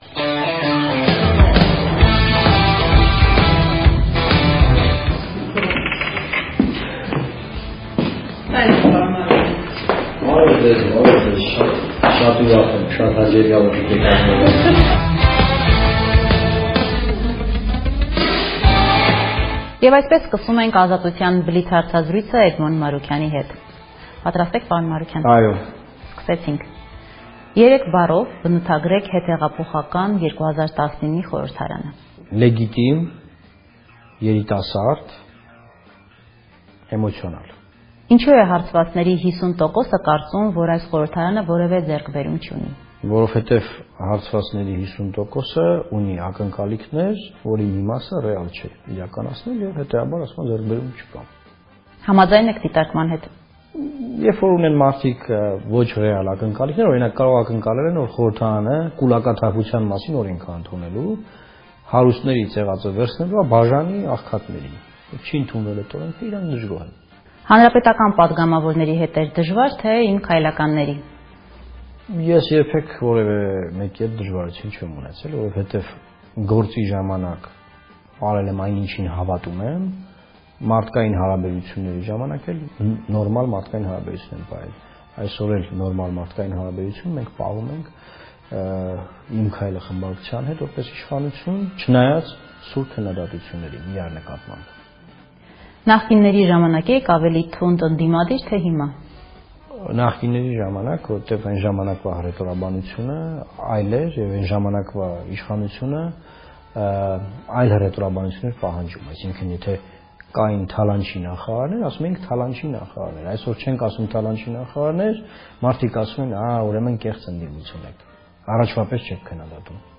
Տոնական բլից հարցազրույց ԱԺ ԼՀԿ խմբակցության ղեկավար Էդմոն Մարուքյանի հետ